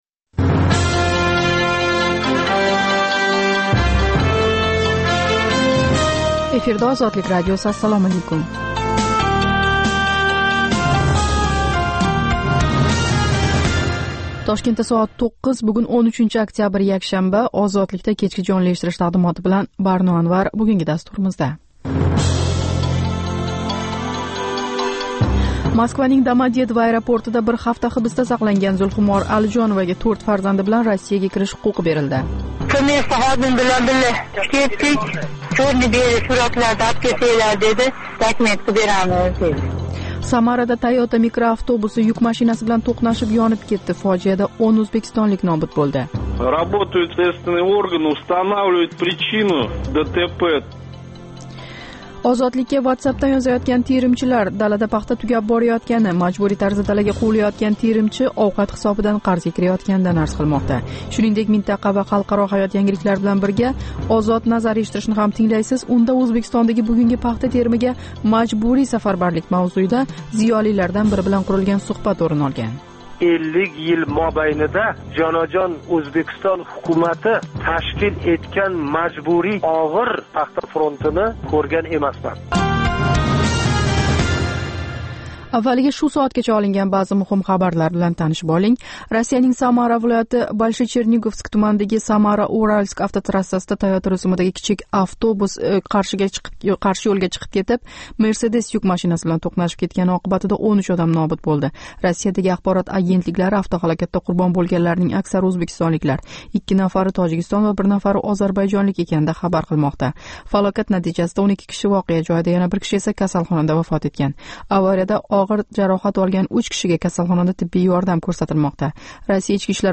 Тошкент вақти билан кечки 9 да бошланадиган жонли дастуримизда сўнгги хабарлар, Ўзбекистон, Марказий Осиë ва халқаро майдонда кечаëтган долзарб жараëнларга доир тафсилот ва таҳлиллар билан таниша оласиз.